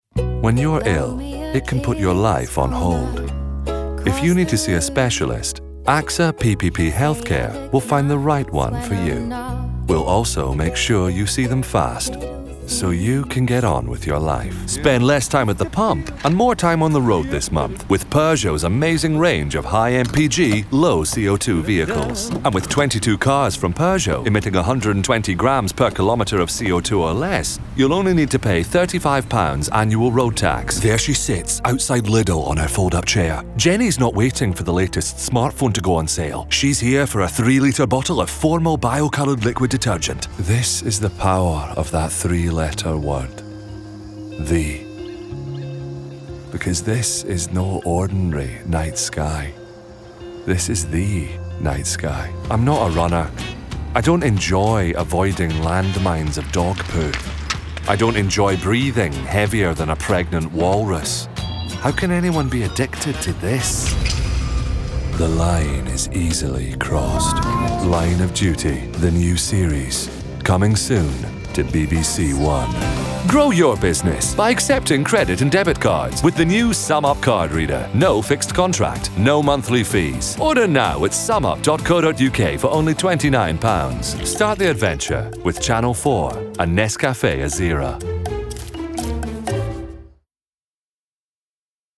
Commercial Showreel
He is proficient in UK and American accents, and his deep, resonant and clear voice has featured in over a hundred audiobooks to date.
Male
Authoritative
Gravelly